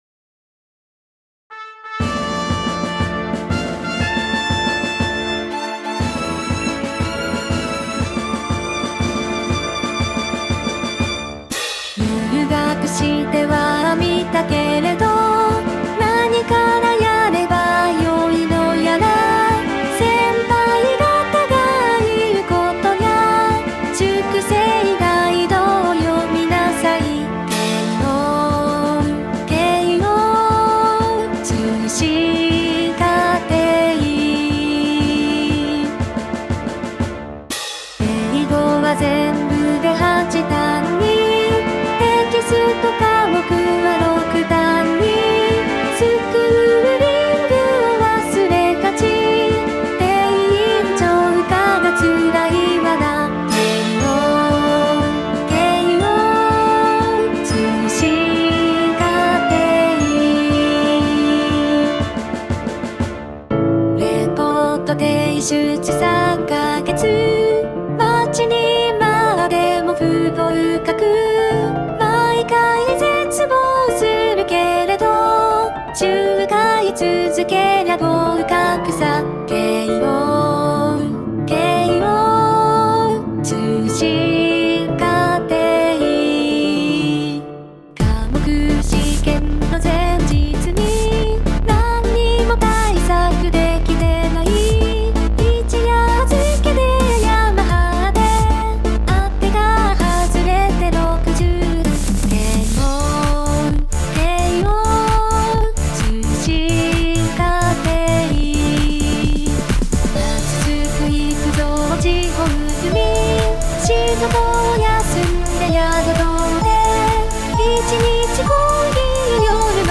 歌にはNEUTRINOというボカロ的なものを使っています。
歌：NEUTRINO めろう(無料)
楽器：無料のVSTi多数